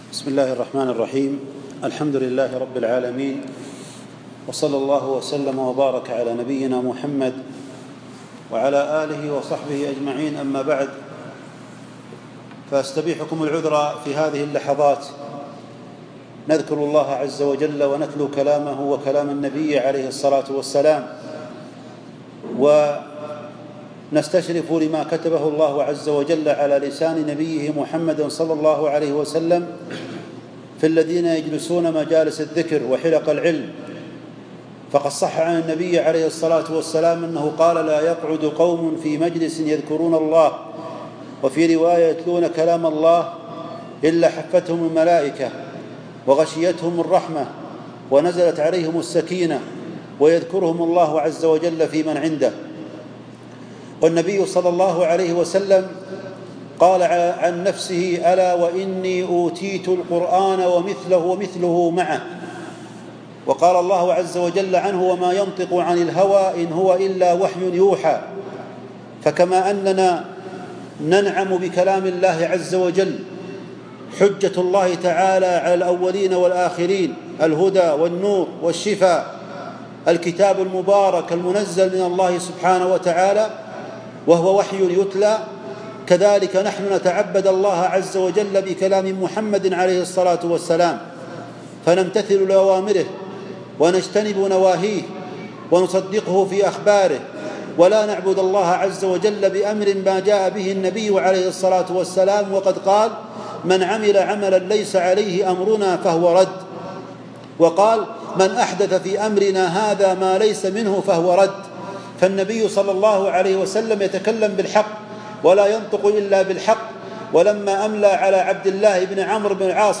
كلمة وعظية - حقيقة لا إله إلا الله